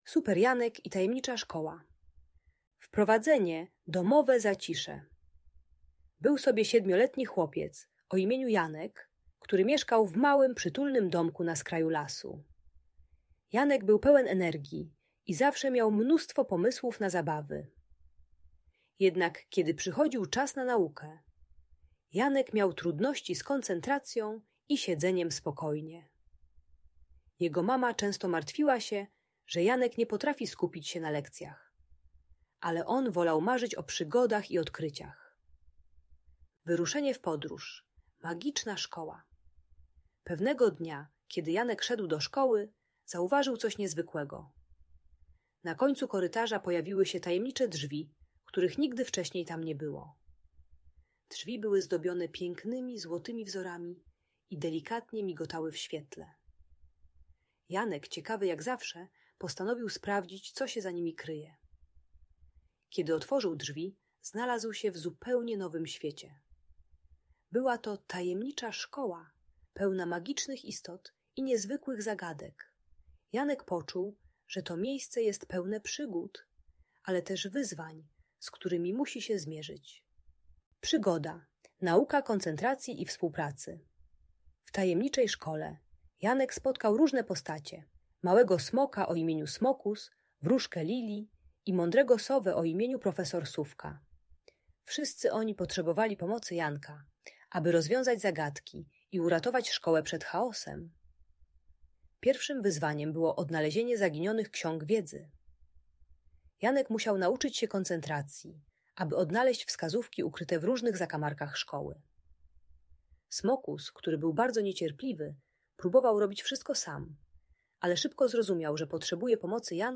Super Janek i Tajemnicza Szkoła - Szkoła | Audiobajka